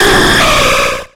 Cri de Ho-Oh dans Pokémon X et Y.